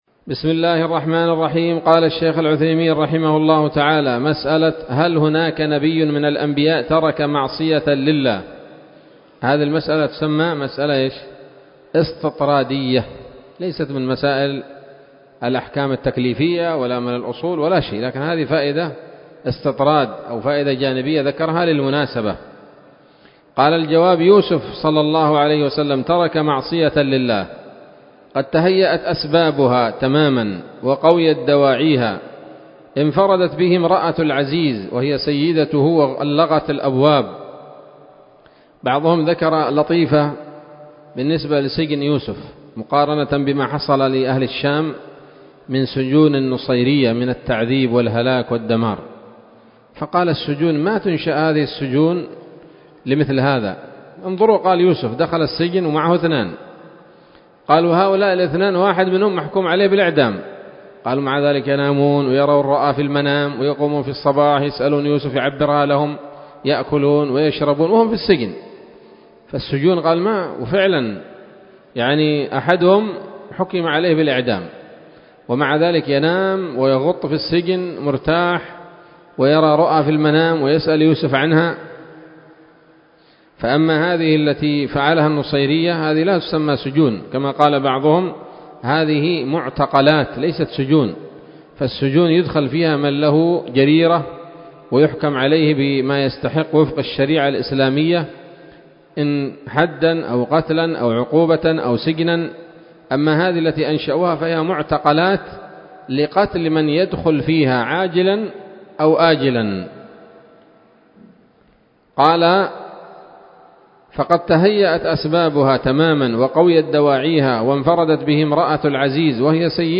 الدرس الخامس عشر من شرح نظم الورقات للعلامة العثيمين رحمه الله تعالى